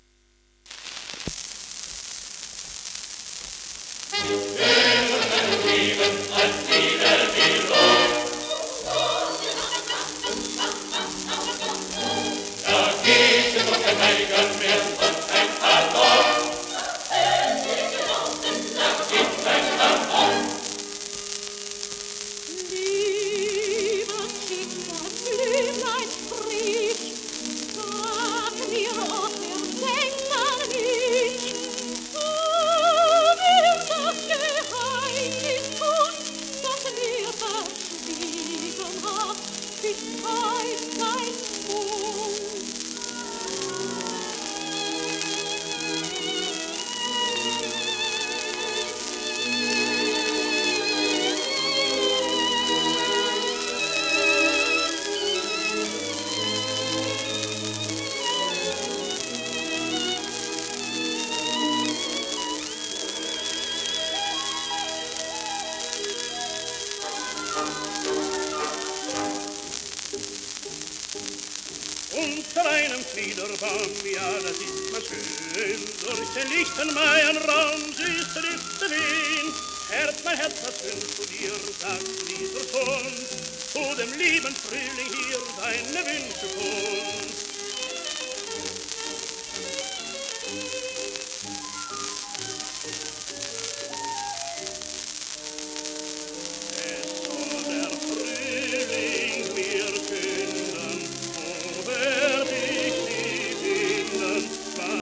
w/不詳(Ten, Sop, コーラス)